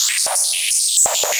RI_RhythNoise_170-03.wav